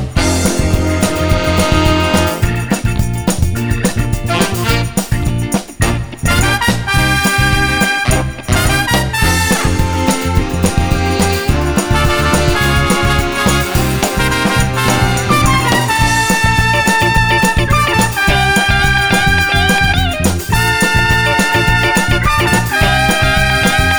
Live Version Pop (1960s) 2:14 Buy £1.50